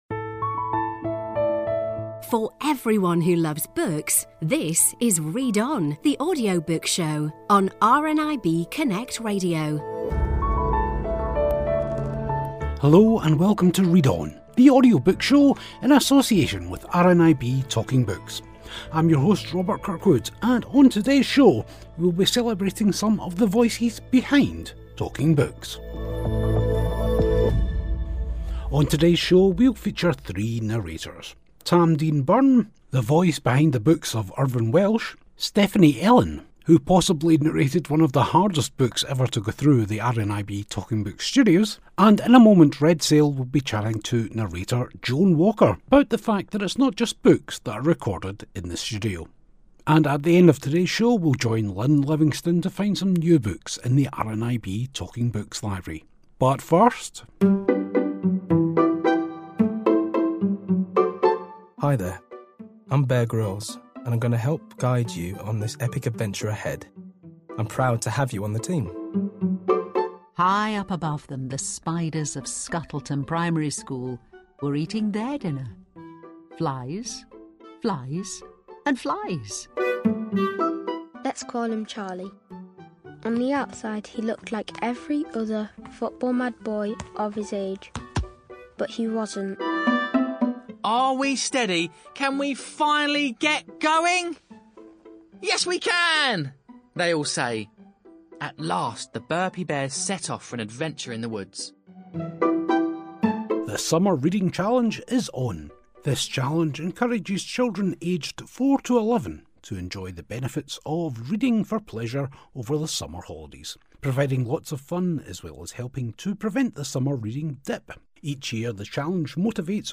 In today's show we talk to three very different narrators.